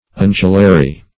Search Result for " undulary" : The Collaborative International Dictionary of English v.0.48: Undulary \Un"du*la*ry\, a. [See Undulate .]